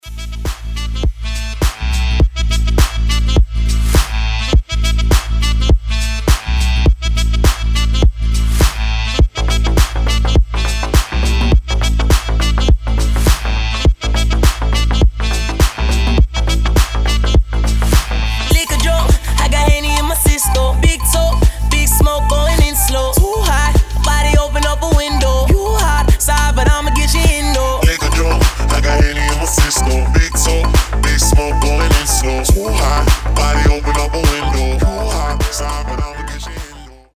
• Качество: 320, Stereo
мужской голос
ритмичные
заводные
басы
Саксофон
house
труба